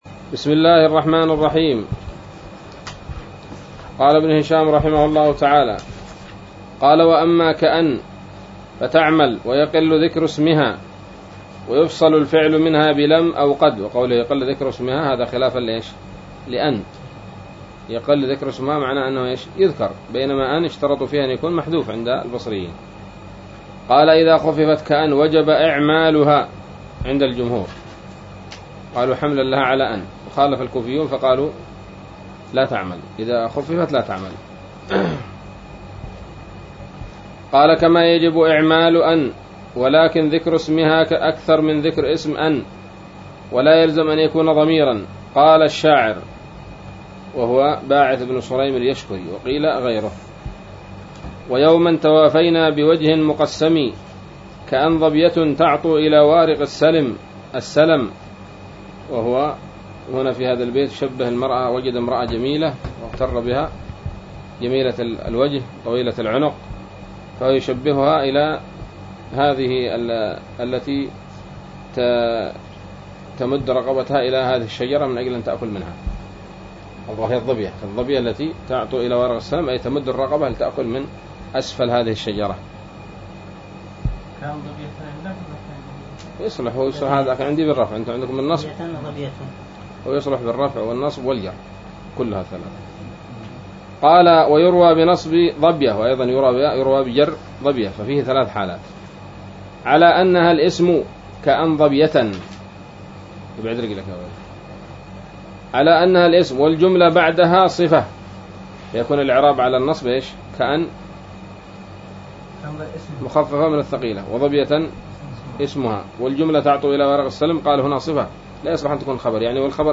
الدرس الخامس والستون من شرح قطر الندى وبل الصدى